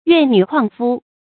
怨女曠夫 注音： ㄧㄨㄢˋ ㄋㄩˇ ㄎㄨㄤˋ ㄈㄨ 讀音讀法： 意思解釋： 指沒有配偶的成年男女。